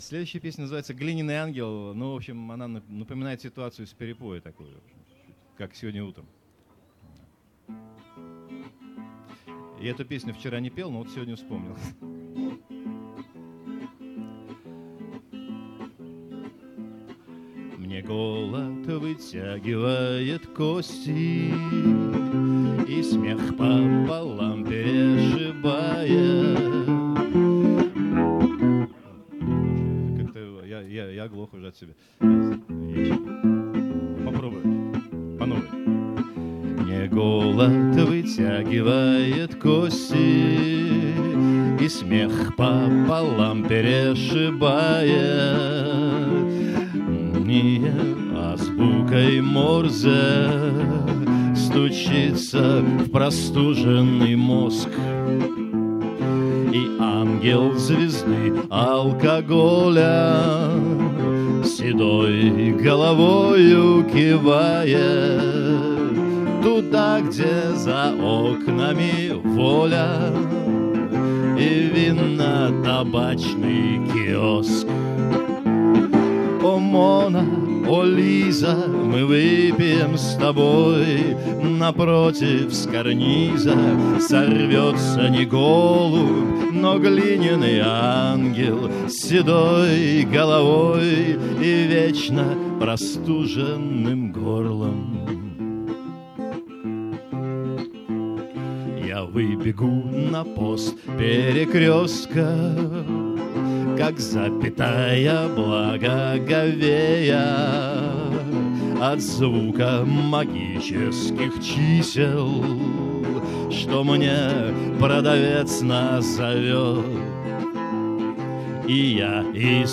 2005 - Граненый слёт